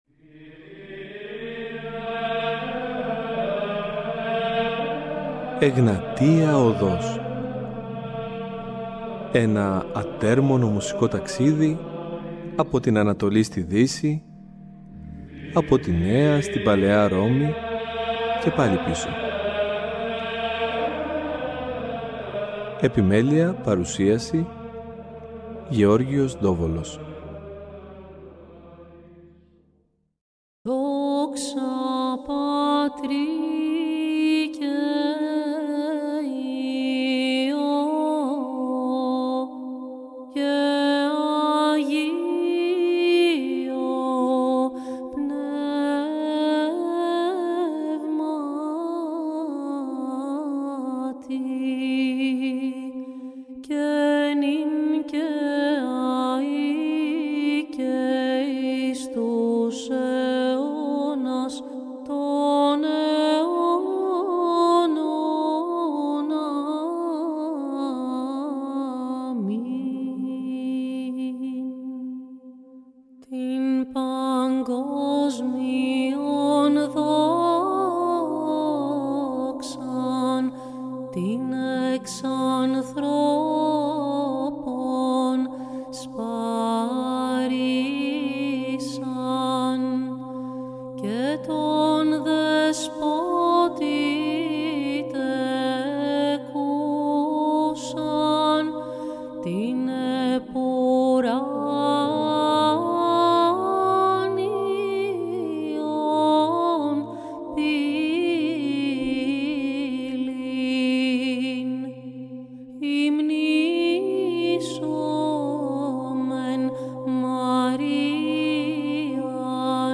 Η γυναικεία φωνή στην ψαλτική τέχνη – Β’ Μέρος